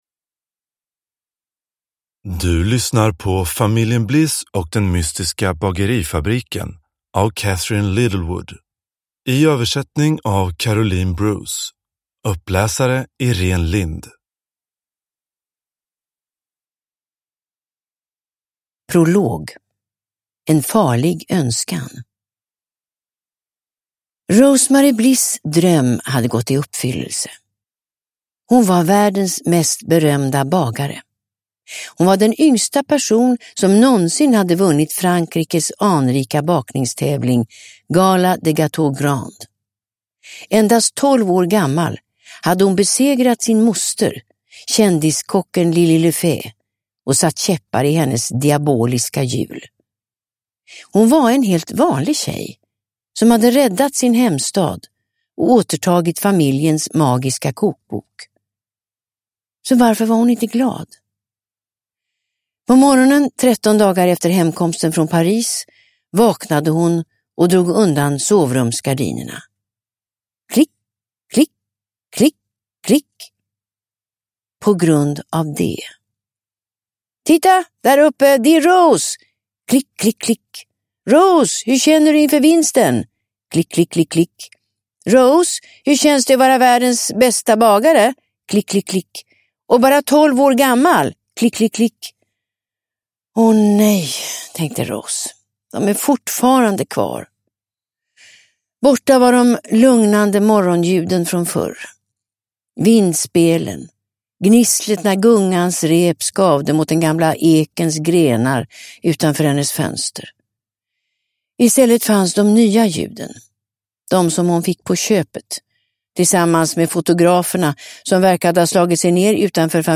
Familjen Bliss och den mystiska bagerifabriken – Ljudbok – Laddas ner